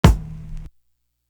Boing Kick.wav